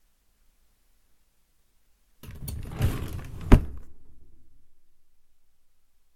Fast/Open End drawer/5
Duration - 6 s Environment - Bedroom, absorption of curtains, carpet and bed. Description - Open, pulled fast wooden drawer, bangs rolls quickly as its grabbed.